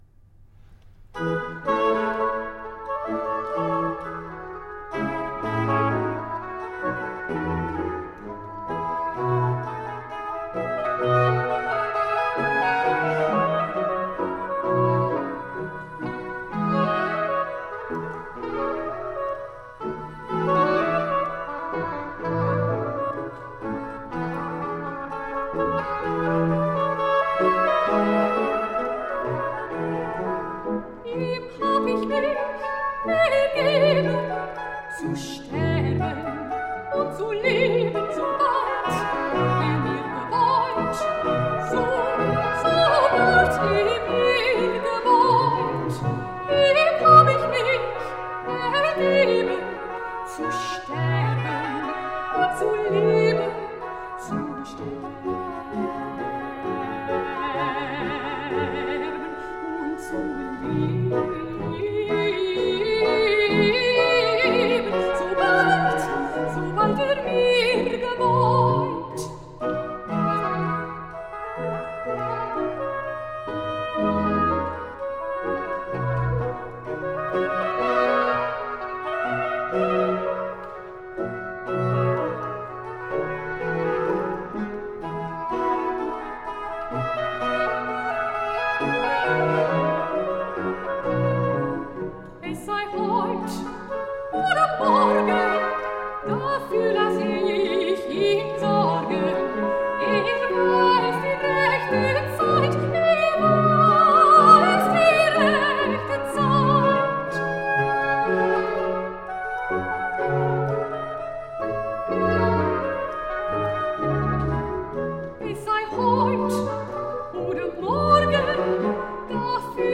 Baroque instrumental and vocal gems
Classical, Baroque, Classical Singing, Harpsichord, Cello
Organ
Violin